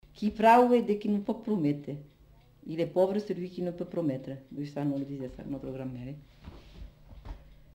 Aire culturelle : Comminges
Lieu : Cathervielle
Type de voix : voix de femme
Production du son : récité
Classification : proverbe-dicton